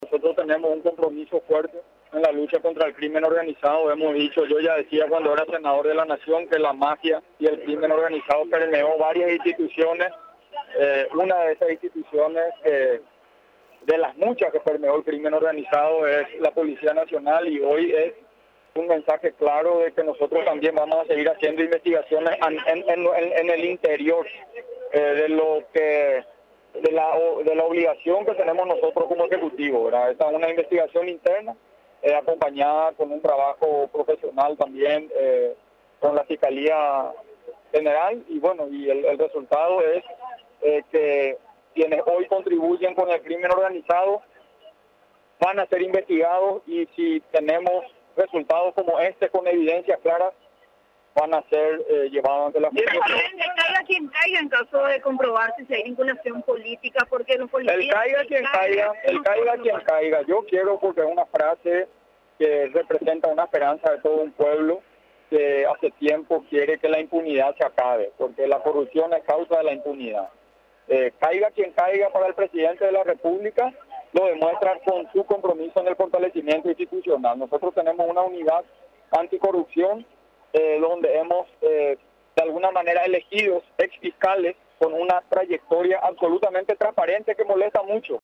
El Jefe de Estado Mario Abdo Benítez, afirmó el compromiso de luchar contra el crimen organizado.